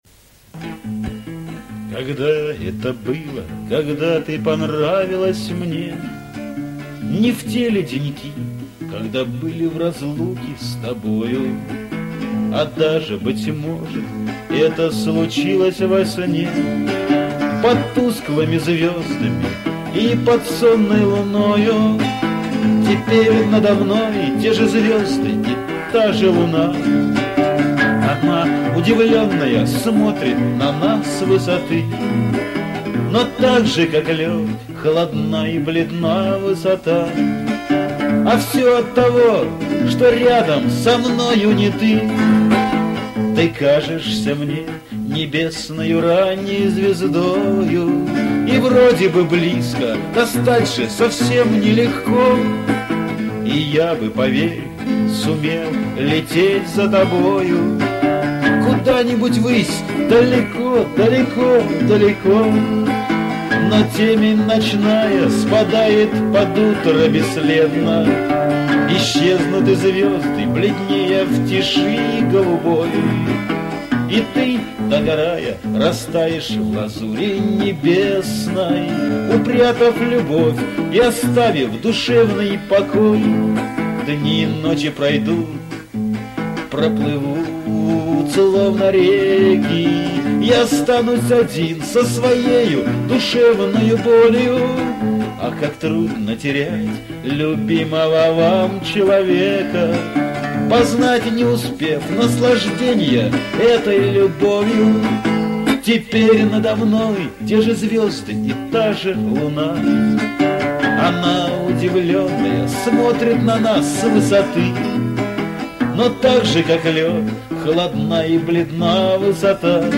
Шансон
Исполнение под гитару